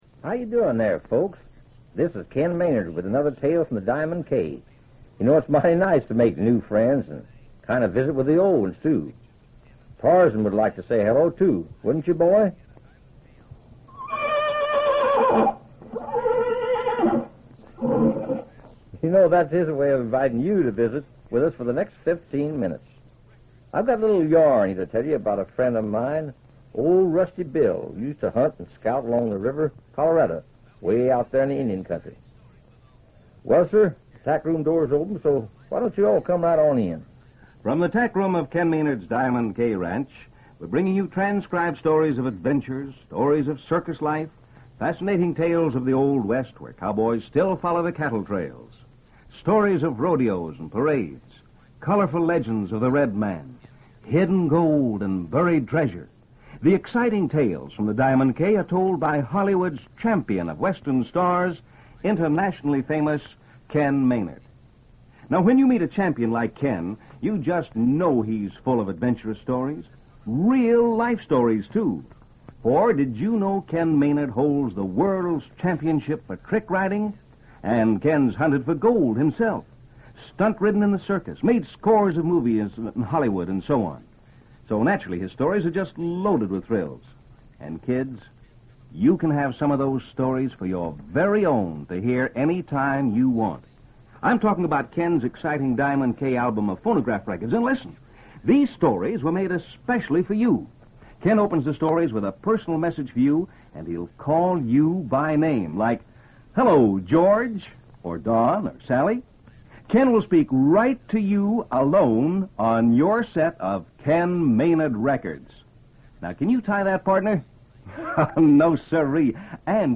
- "Tales from the Diamond K" was a syndicated radio show aimed at a juvenile audience, broadcasted during the mid-1950s. - The show featured a variety of stories, mostly set in the Old West, and was designed to entertain and educate its young listeners. - Ken Maynard, a pioneer singing cowboy and film star, hosted the show, introducing a different story each day.